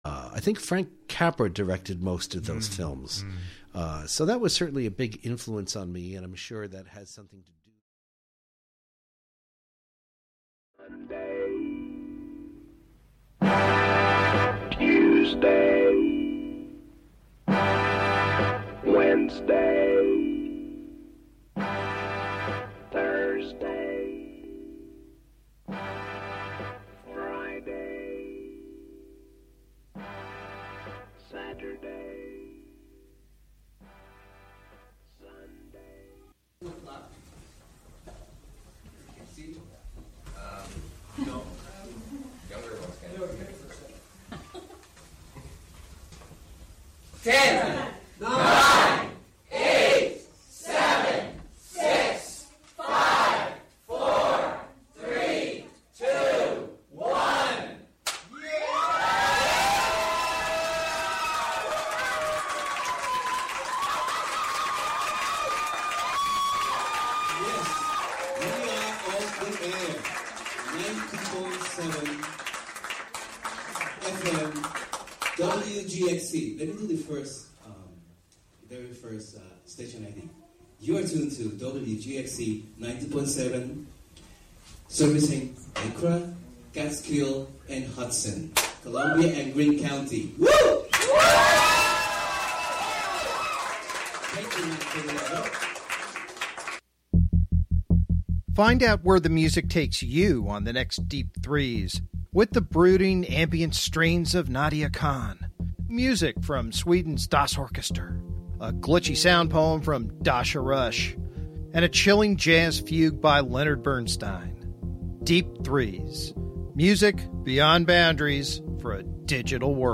plays the Mellotron
pipe organ